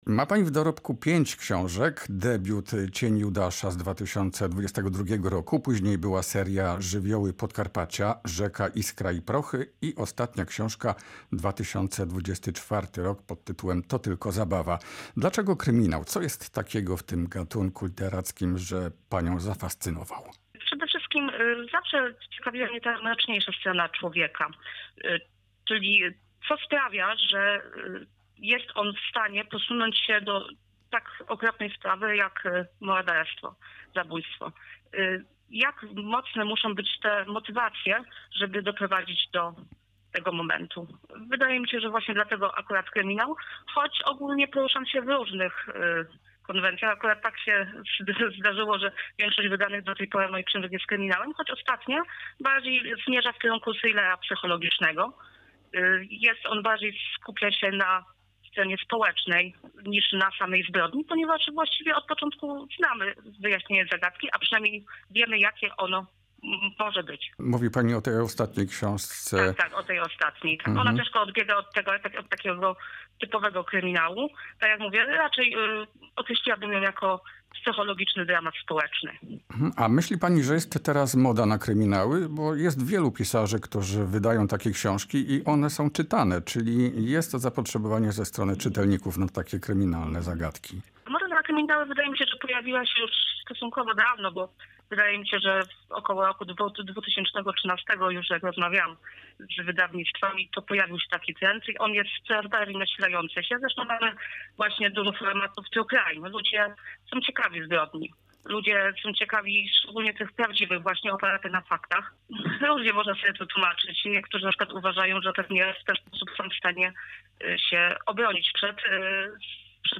Rozmowa z pisarką, autorką kryminałów